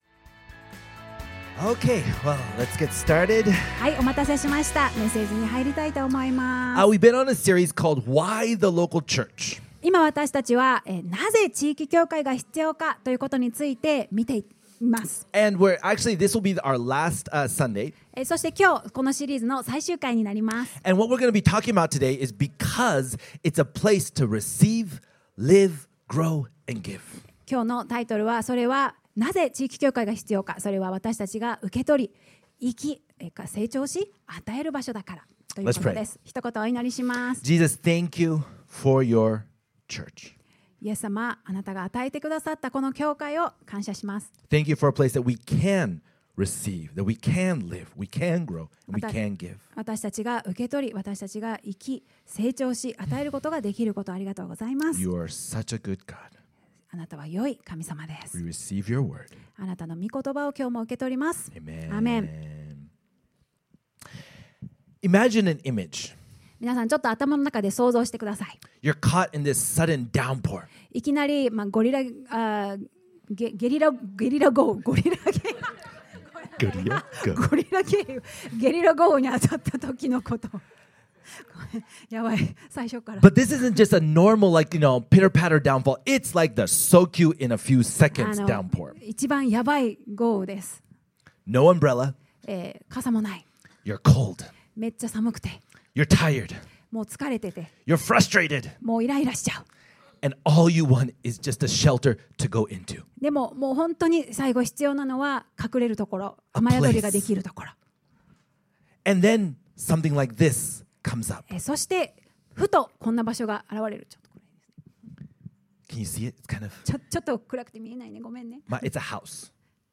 #4 MP3 PDF SUBSCRIBE on iTunes(Podcast) Notes Sermons in this Series あなたが受け取り、生き(成長し）、与える場所だから Because it's a place to receive, live (grow), and give なぜ、地域教会？